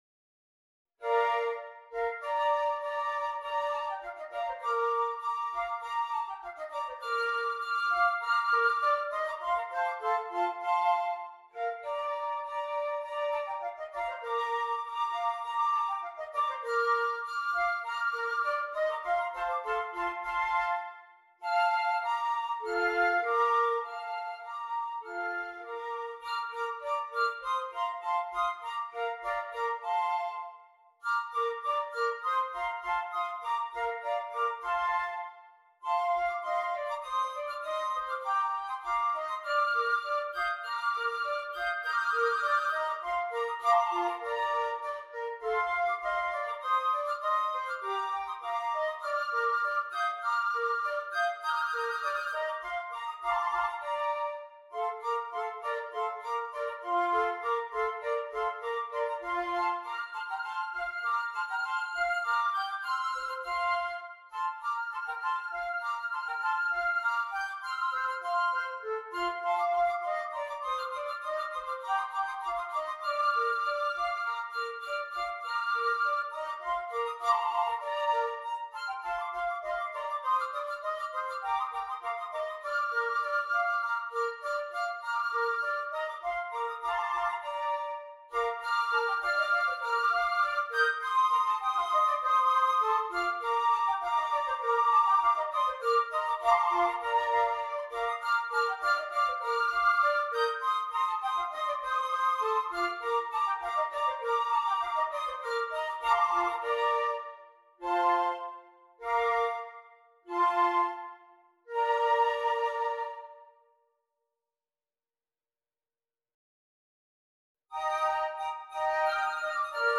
6 Flutes
flute ensemble
they have been set in two choirs of three flutes each